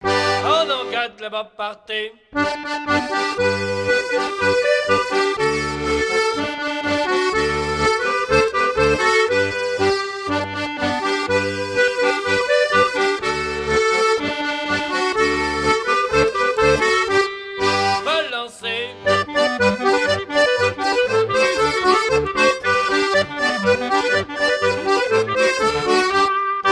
danse : quadrille : avant-quatre
Pièce musicale éditée